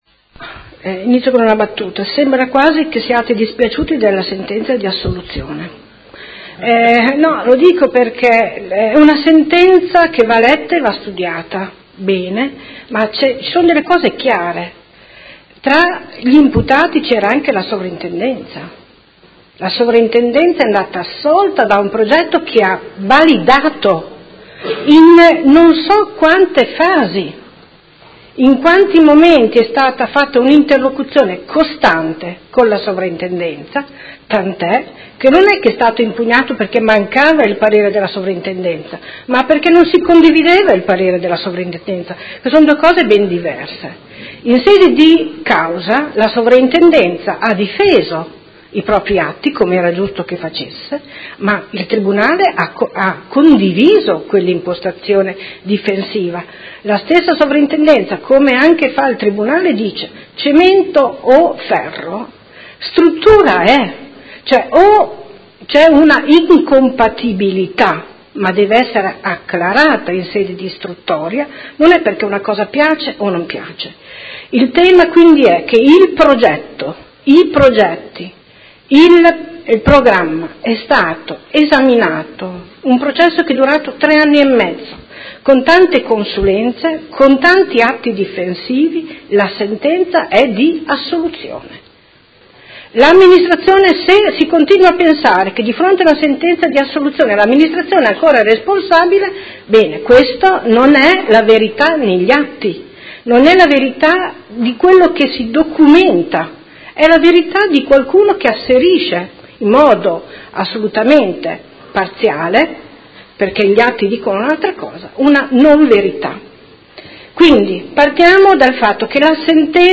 Seduta dell'11/01/2018 Conclusioni. Interrogazione del Consigliere Morandi (FI) avente per oggetto: Dopo la sentenza del Tribunale di Modena che ha assolto i tecnici comunali e liberato dal sequestro i chioschi del Parco delle Rimembranze, questi potranno essere aperti e fornire il servizio utile che tutti i cittadini chiedono?